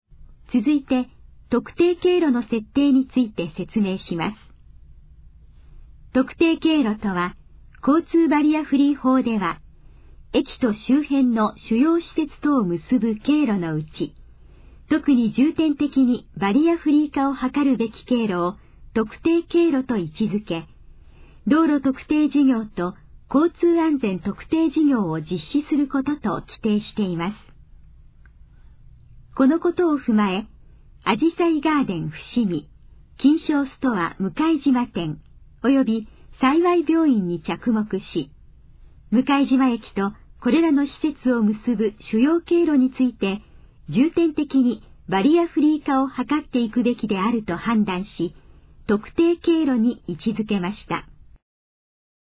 以下の項目の要約を音声で読み上げます。
ナレーション再生 約105KB